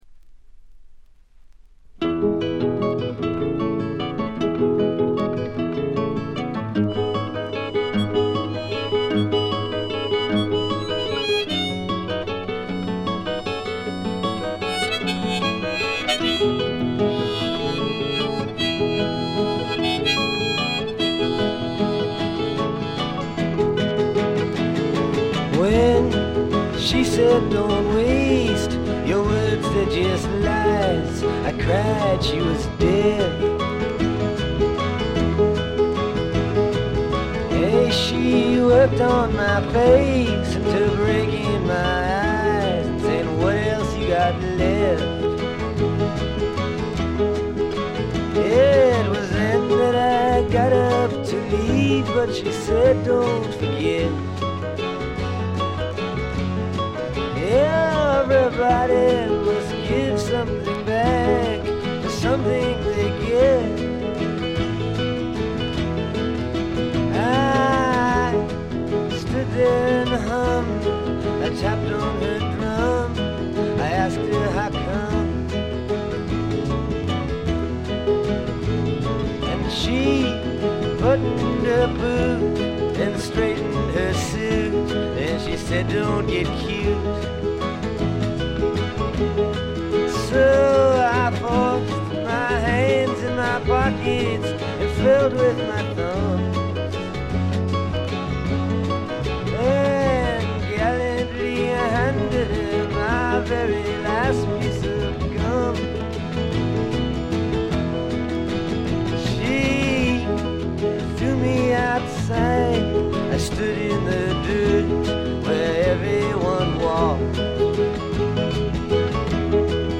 試聴曲は現品からの取り込み音源です。
vocals, guitar, harmonica, piano